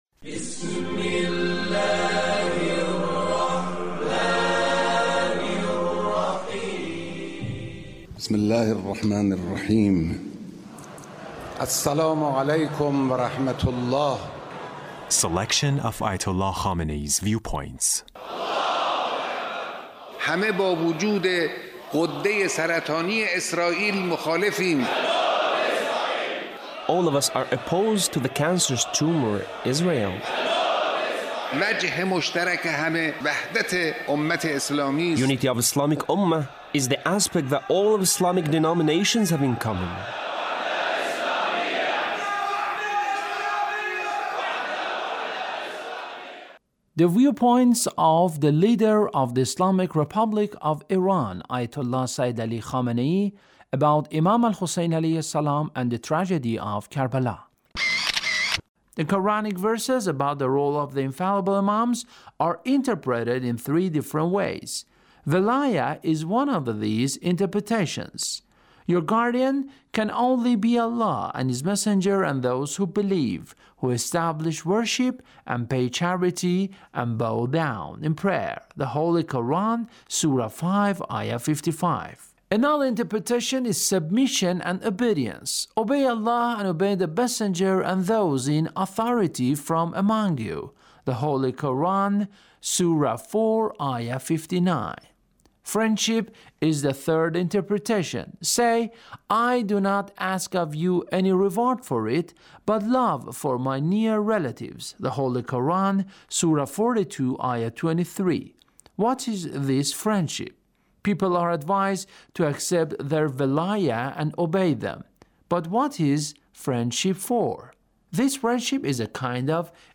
Leader's Speech (1783)